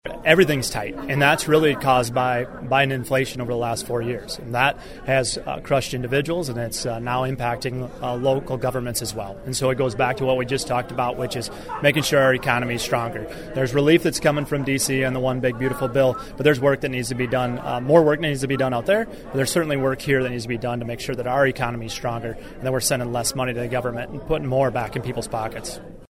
Crabtree appeared at a meeting of “Republican Friends” in Yankton.